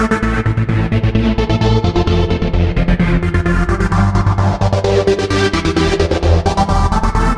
在钢琴上跳舞 第二合成器
Tag: 130 bpm Dance Loops Synth Loops 1.24 MB wav Key : Unknown